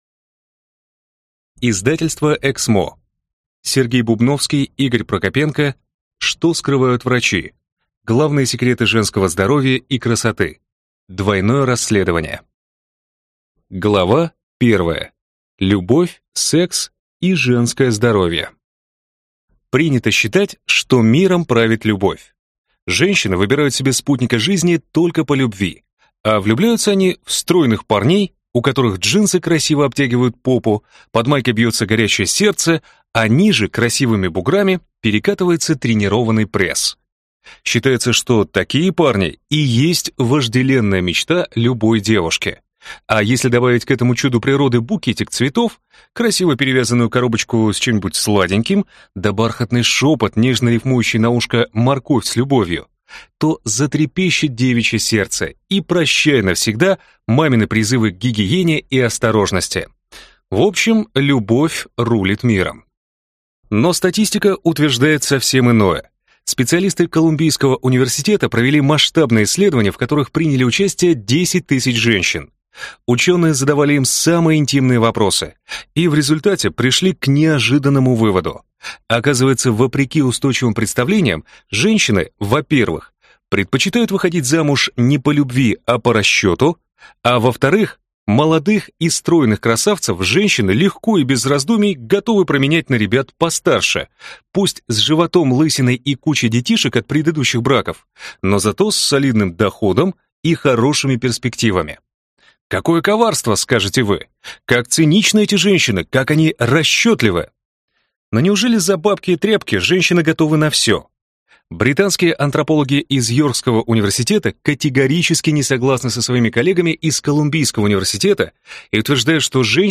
Аудиокнига Что скрывают врачи? Главные секреты женского здоровья и красоты | Библиотека аудиокниг